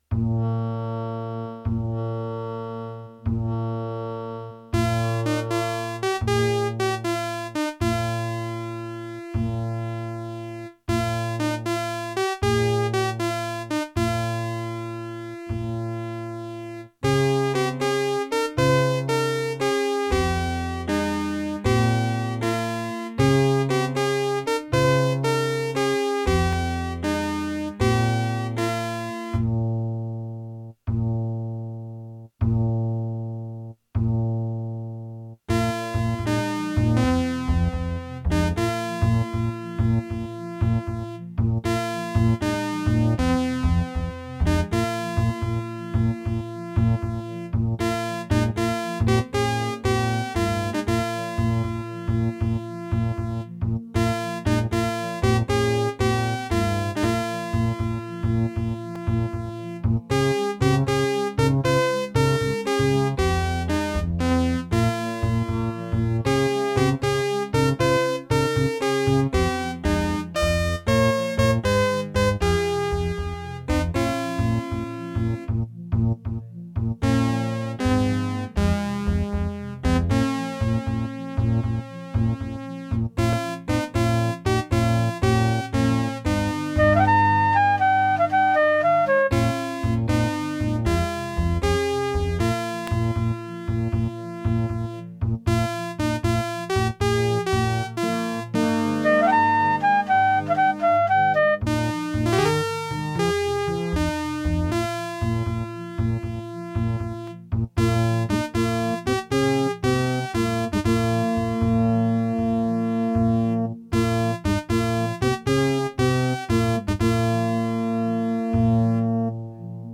Music: midi
Gravis Ultrasound PNP (MT32 emulation megaem)